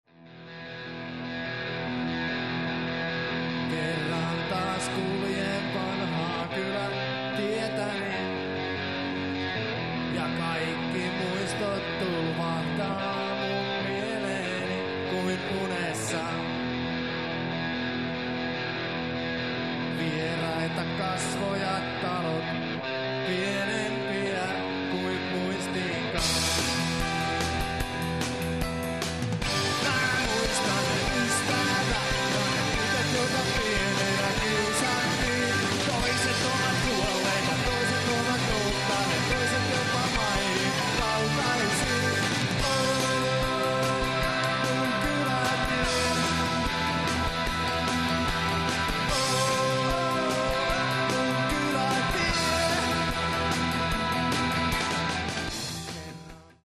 (Compressed/Mono 388KB)
KAIKKI ON TÄYSIN LIVENÄ ÄÄNNELTYÄ